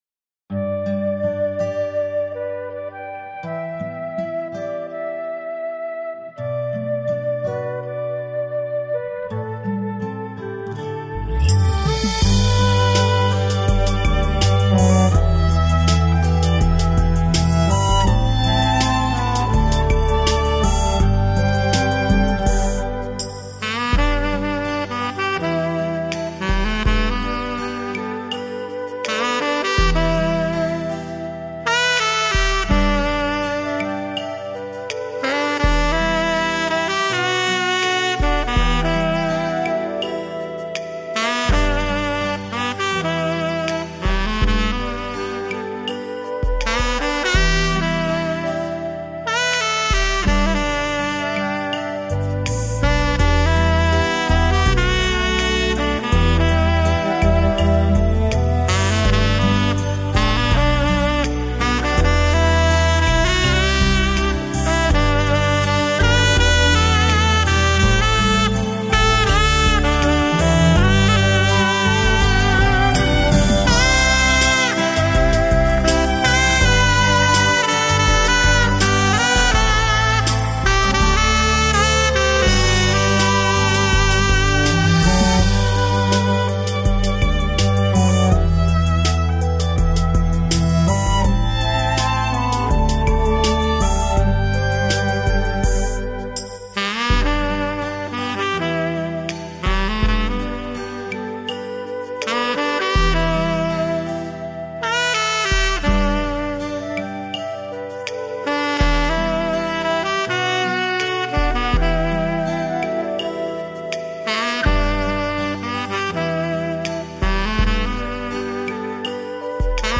类型: 轻音乐
音色暖神沁心，情浓意蜜。